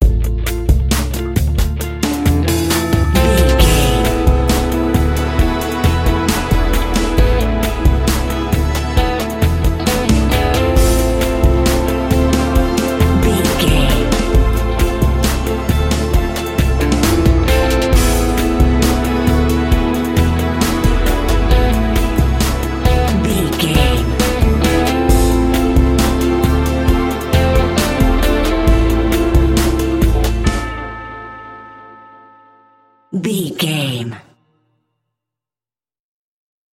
Aeolian/Minor
drums
electric guitar
bass guitar